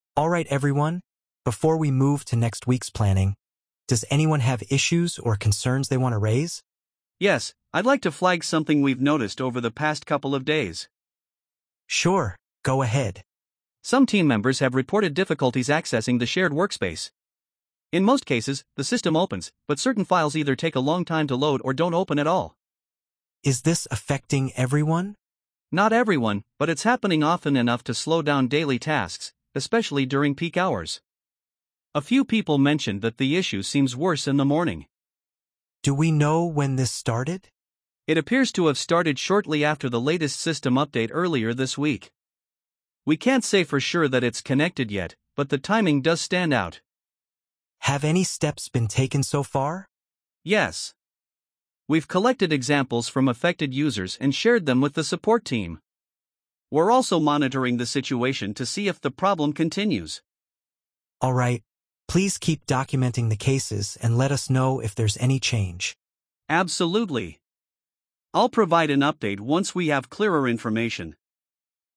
🤝 A team meeting where employees can raise concerns.